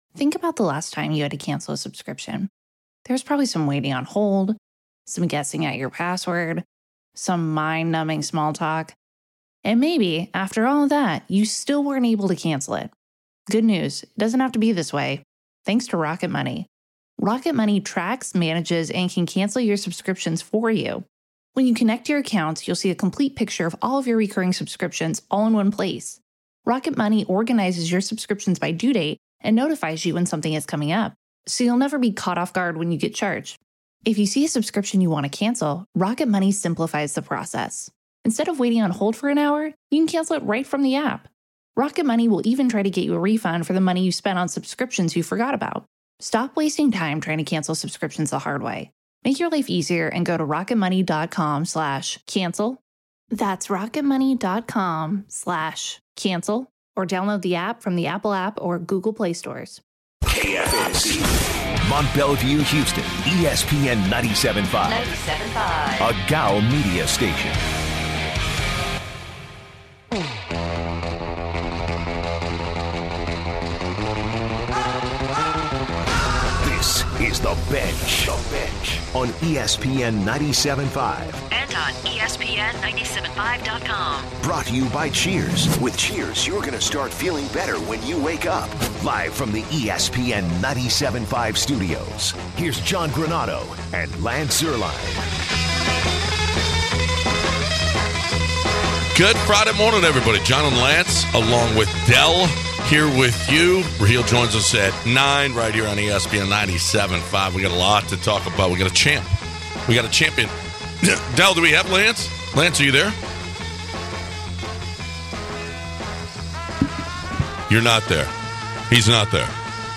Toronto Raptors are world champions! What are the repercussions of this loss for the Warriors? The guys talk off-season free agency for Kawhi. The guys get a call from Chairman Kim Jong-un to discuss his thoughts on the Finals.